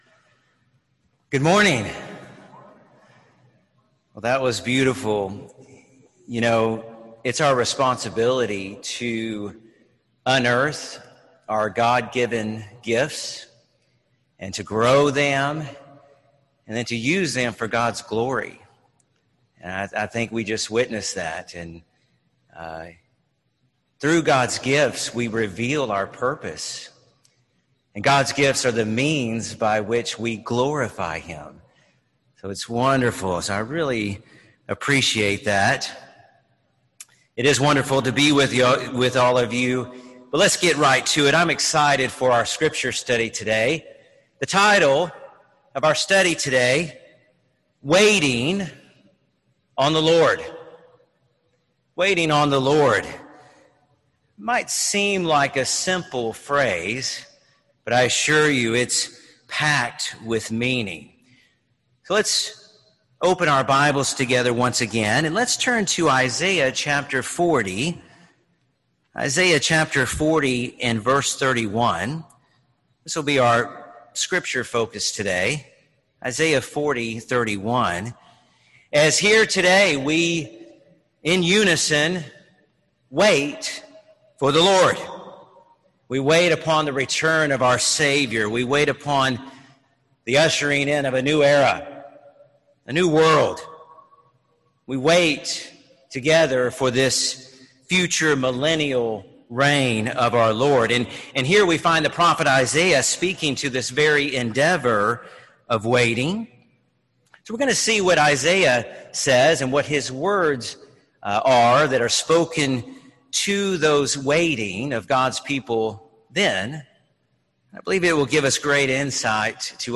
This sermon was given at the Daytona Beach, Florida 2020 Feast site.